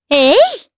Shamilla-Vox_Attack2_jp.wav